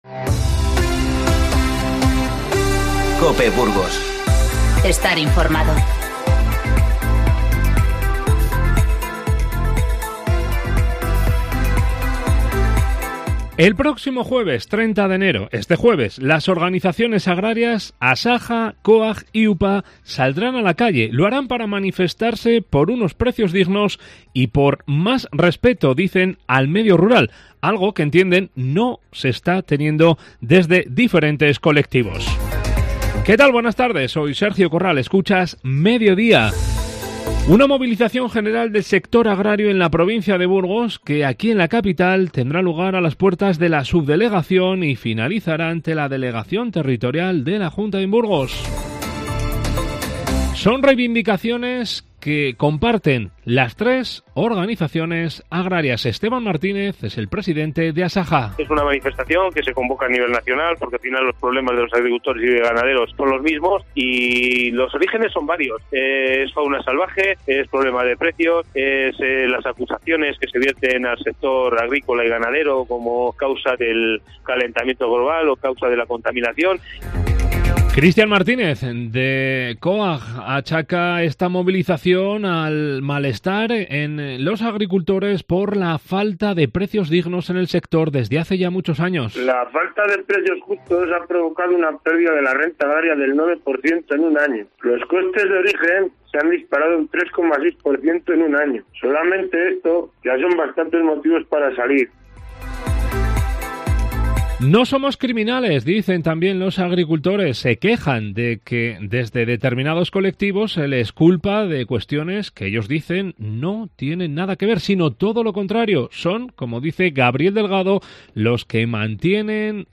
Informativo 27-01-20